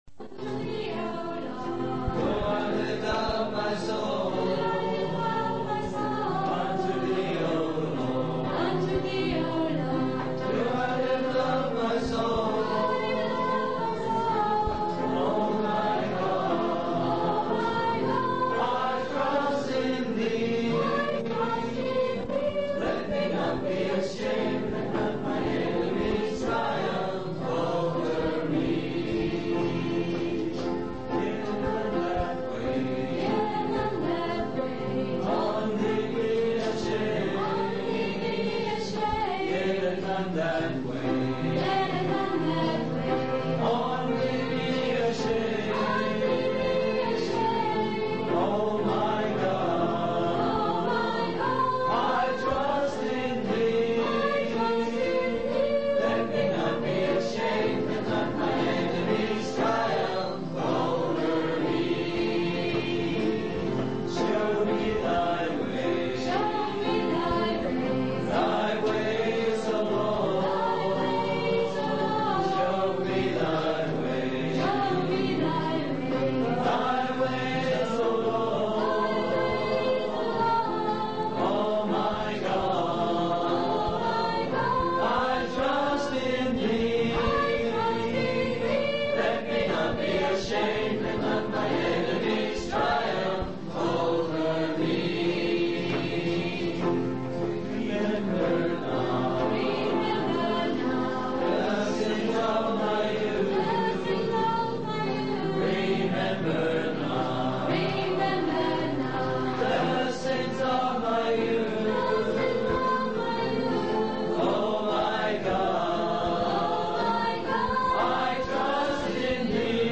In this sermon, the speaker initially considers sharing his personal reasons for being a Christian but decides to give an overview of Operation Mobilization (OM) worldwide instead. He highlights that many people in OM have a localized view and emphasizes the importance of the command of Jesus to go into all the world and preach the gospel. The speaker recounts the early days of OM, where they opened bookshops and engaged in various projects globally.